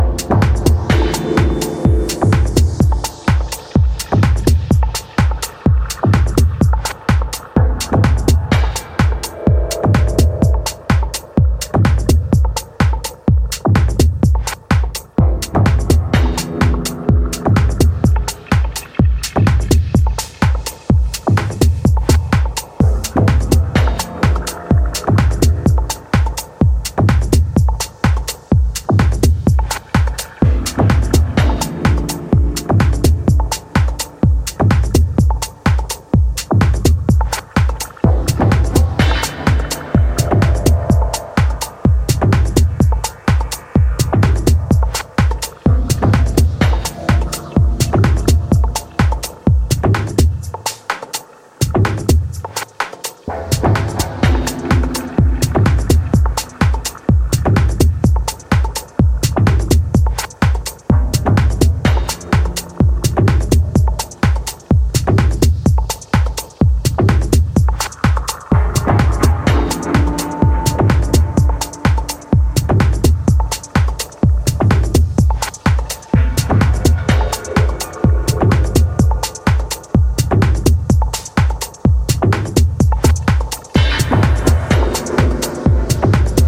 a tightly wound, pulse-driven Tech House trip.